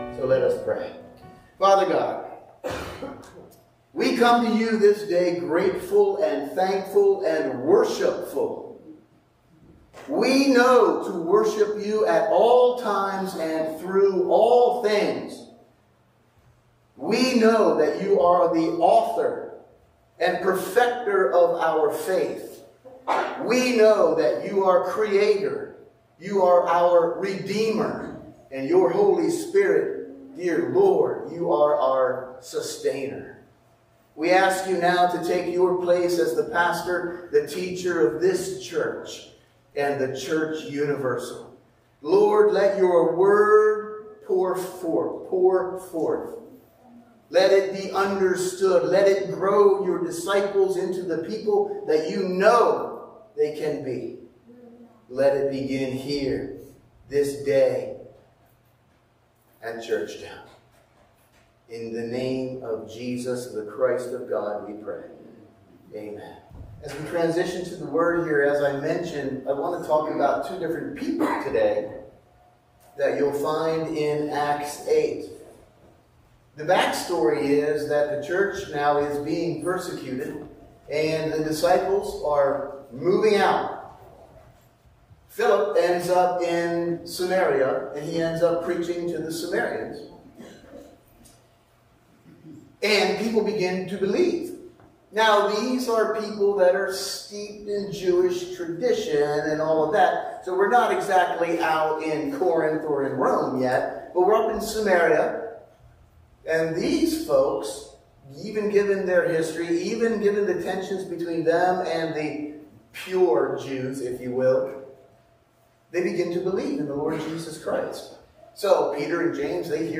Sunday Morning Service – November 24, 2024 – Churchtown Church of God